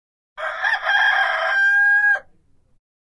Звуки петуха
На этой странице представлена коллекция звуков петуха: от классического «кукареку» до заливистых многоголосых криков.